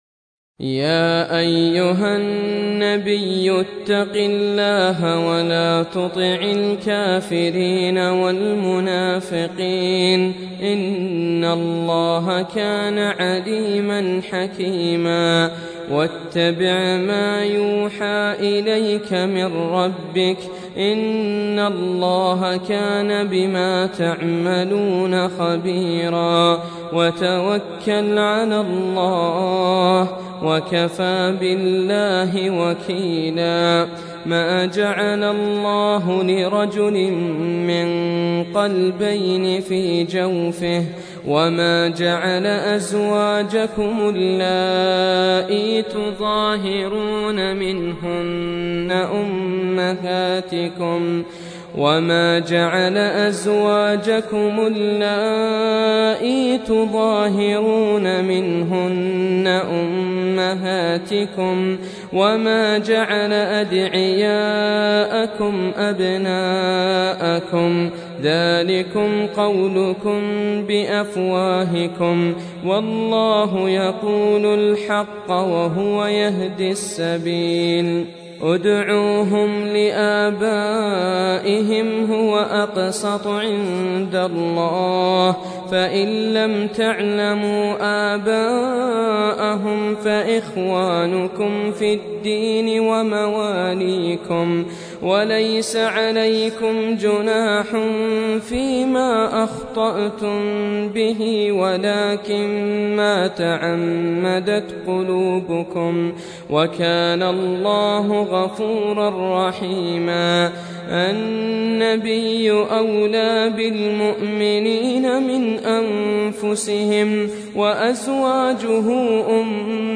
Surah Sequence تتابع السورة Download Surah حمّل السورة Reciting Murattalah Audio for 33.